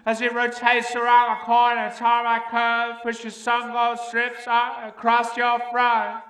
10 Boiling In Dust Vox Bike Vision.wav